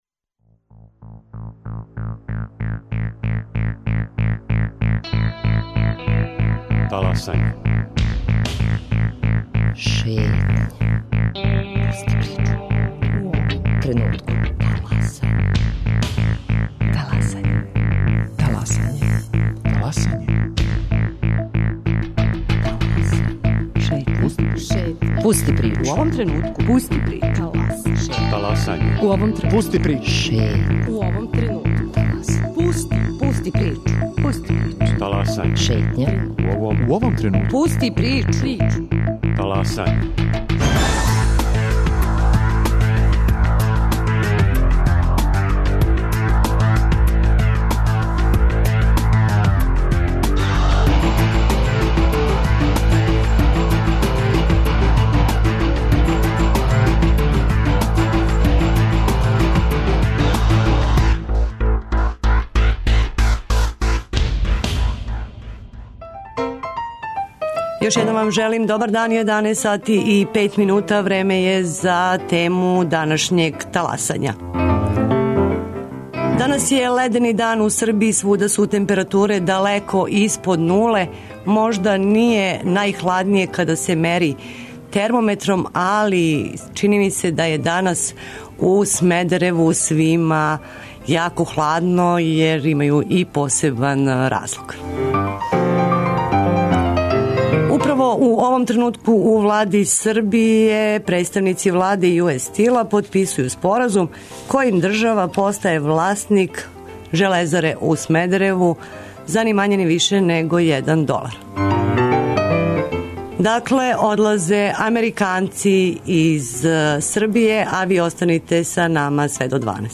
У Таласању на та питања одговарају економисти, представници синдиката и градоначелник Смедерева.